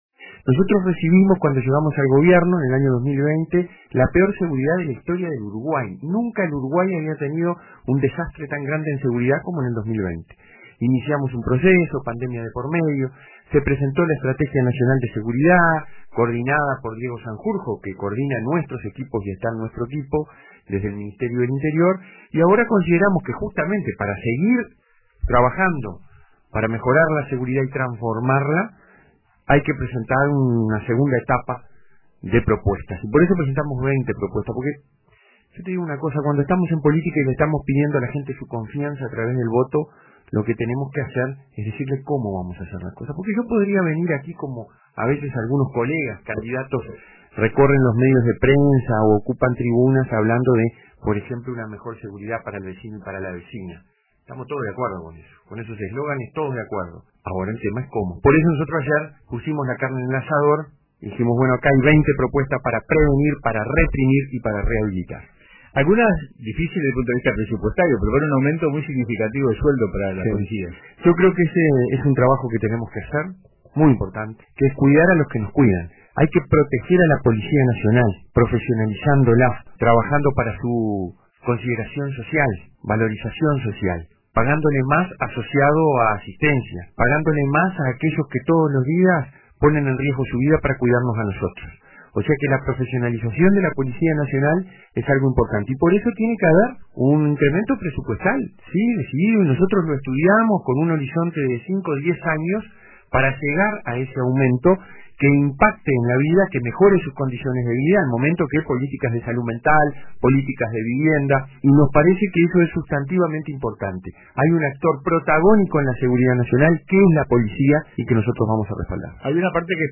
La gira comenzó en Piriápolis y continuó en los estudios de RADIO RBC, donde fue recibido en el programa RADIO CON TODOS.
Durante la entrevista, Silva habló sobre las 20 propuestas para la seguridad que presentó el lunes 1 de abril.